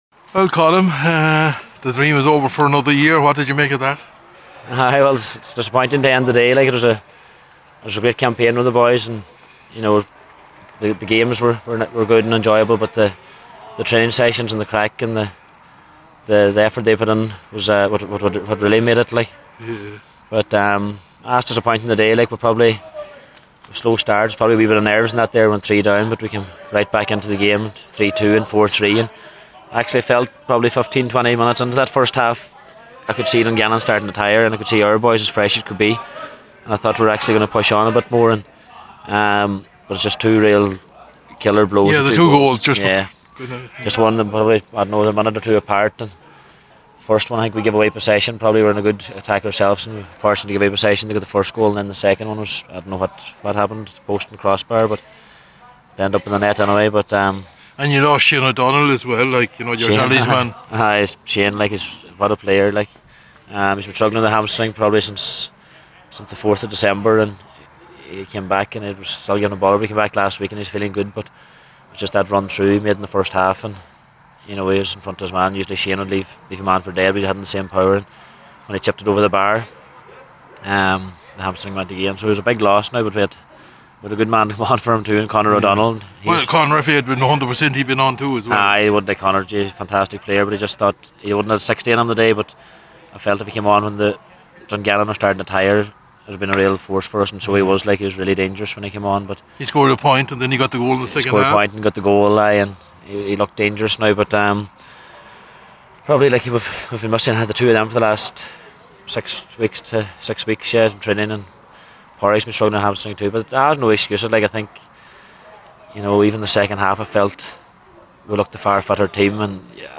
MacRory Cup: St. Patrick’s, Dungannon to strong for St. Eunan’s College in Letterkenny – FT Report & Reaction